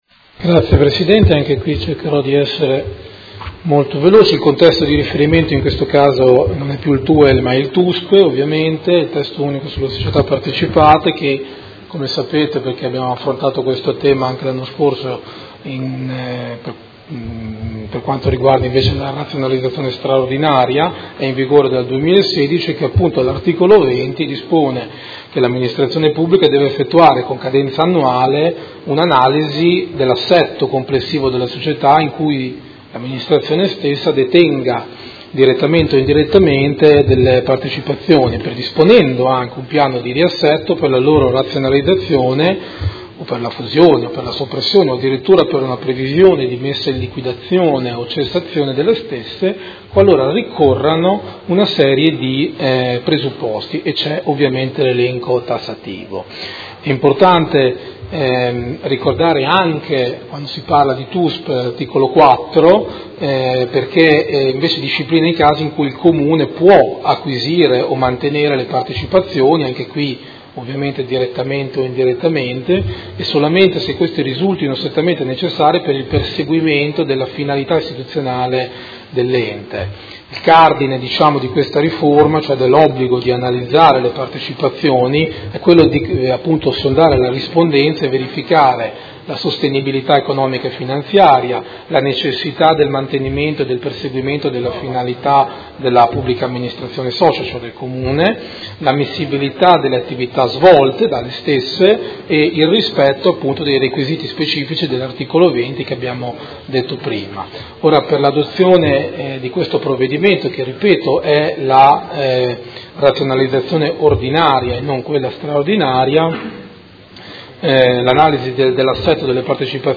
Audio Consiglio Comunale
Seduta del 13 dicembre 2018